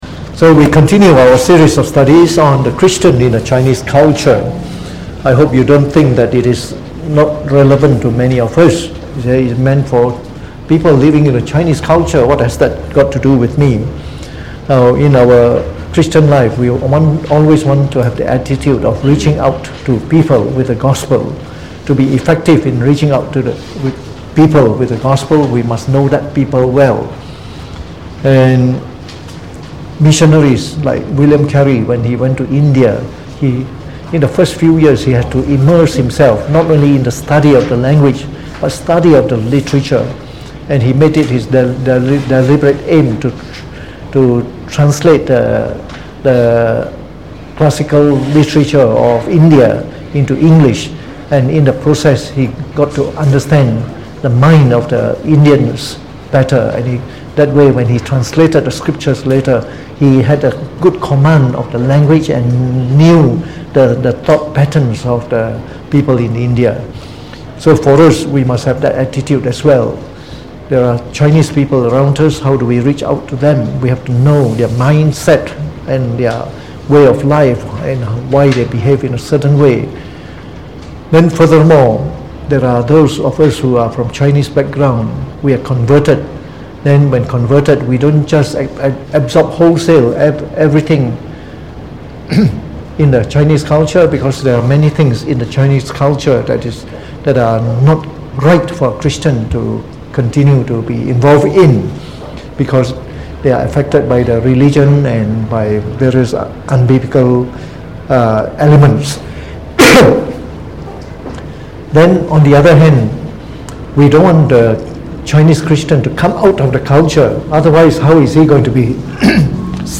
Delivered on the 9th of October 2019 during the Bible Study, from the series on The Chinese Religion.